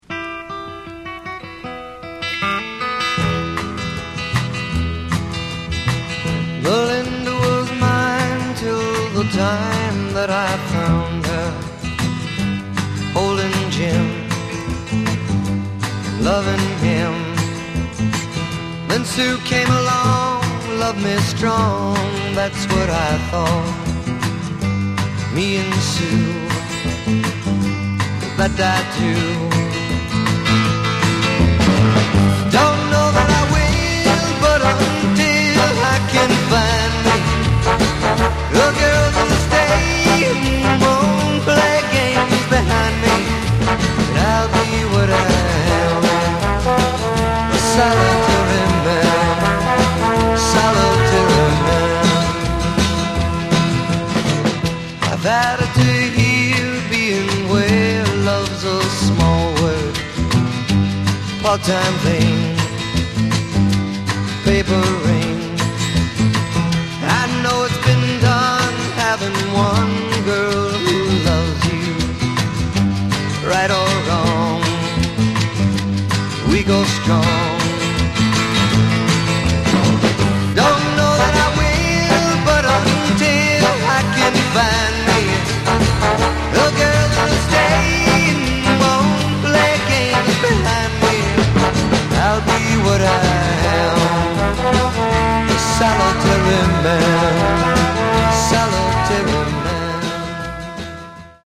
Genre: Folk Rock